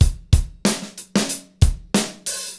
BEAT 7 9306R.wav